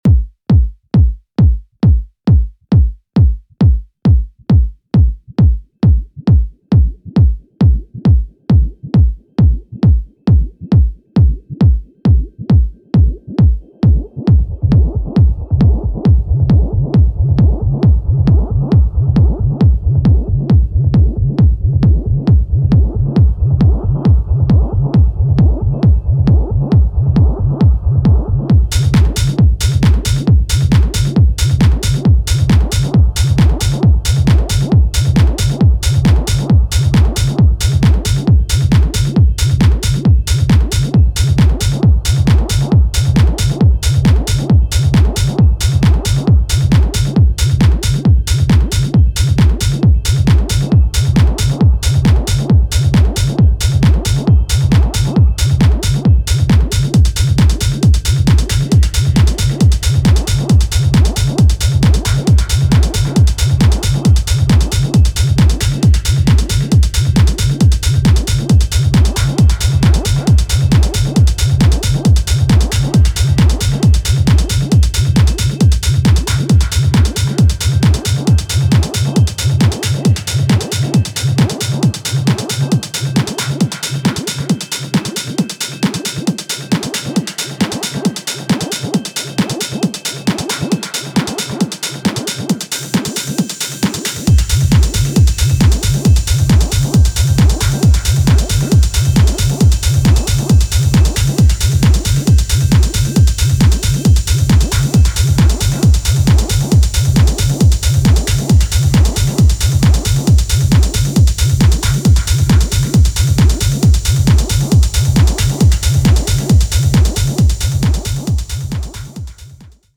ダークに蠢くローエンドを基調に、ピークタイムに彩りを添えるストレートジャッキンキラー
タイトでテンション高いグルーヴが揃いました。